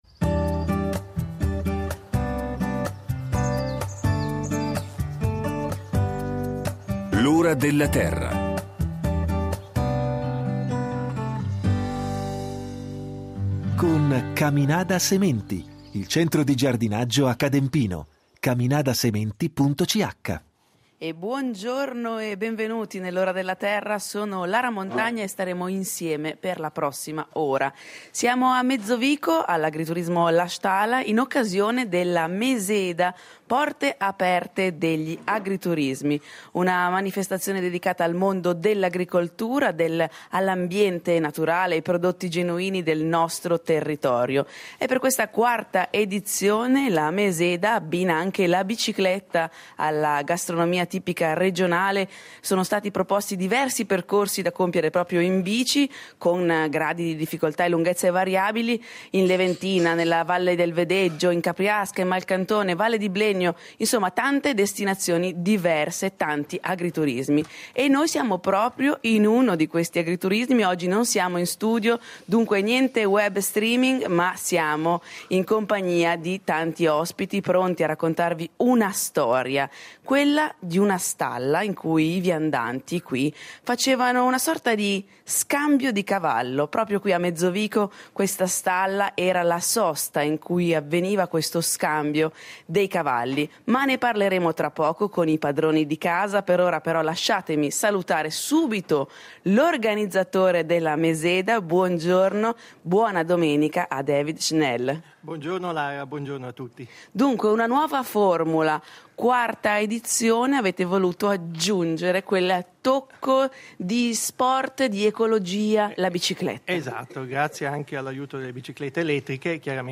In occasione de La Meseda - porte aperte degli agriturismi. E’ la manifestazione dedicata al mondo dell’agricoltura, all’ambiente naturale, ai prodotti genuini del nostro territorio.